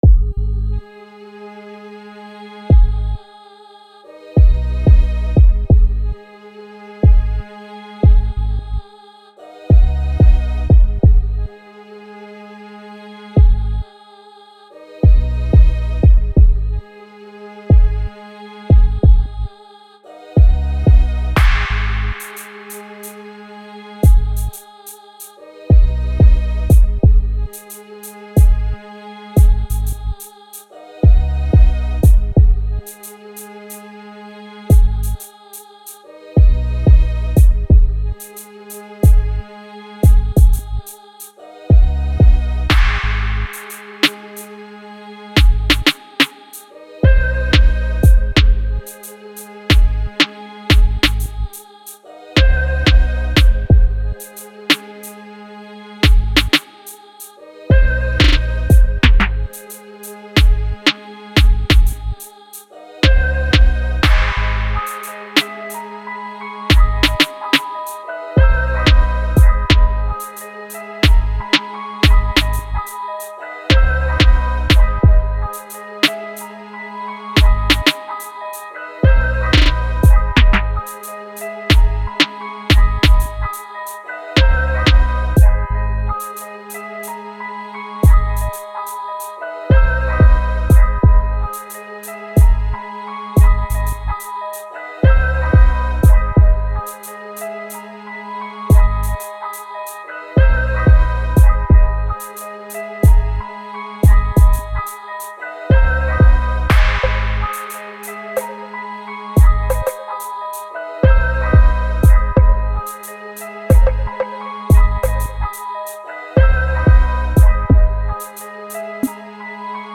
04:20 Genre : Hip Hop Size